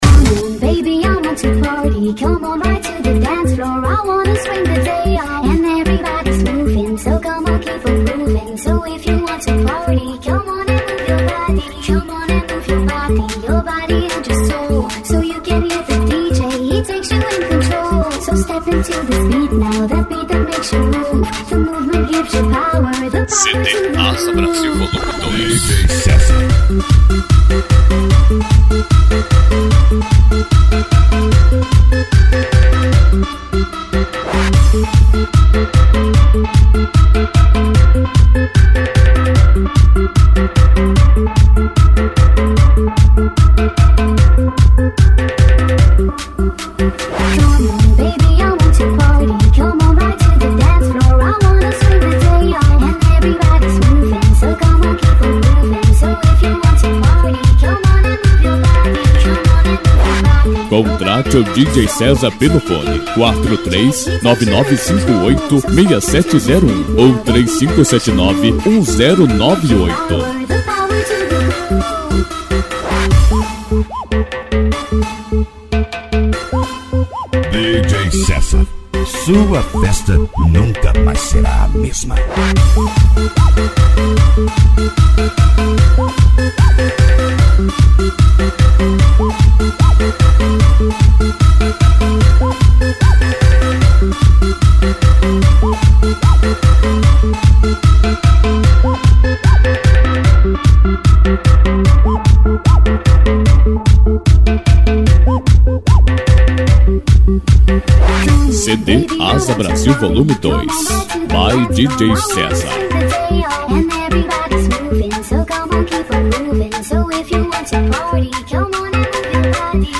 Funk